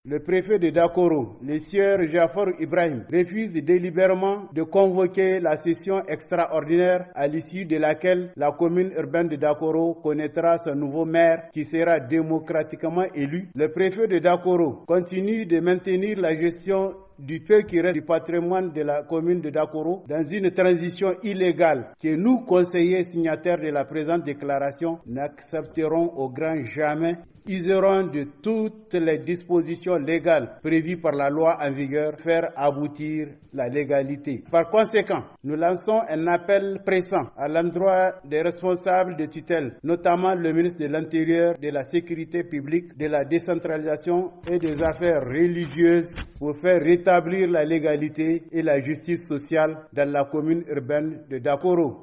La déclaration est lue par Abou Abarchi Membre du Conseil Communal.